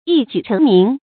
注音：ㄧ ㄐㄨˇ ㄔㄥˊ ㄇㄧㄥˊ
讀音讀法：
一舉成名的讀法